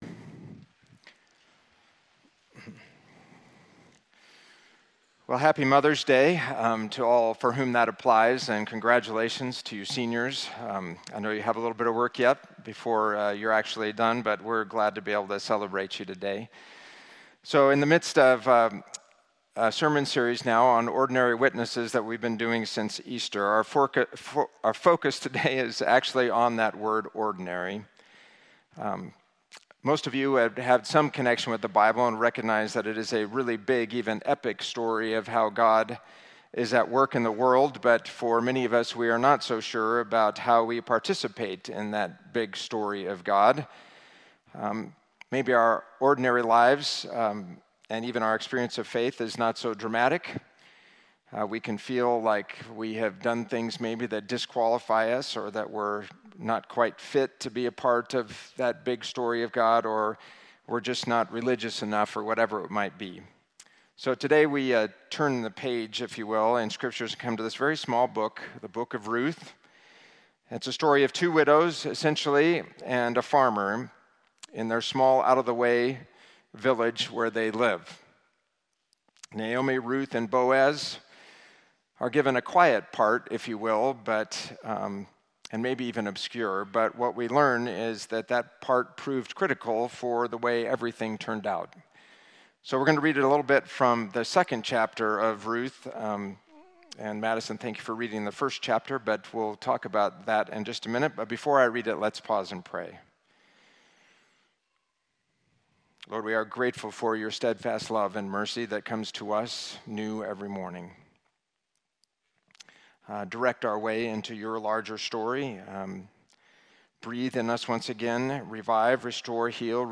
sermon from traditional service on May 12, 2024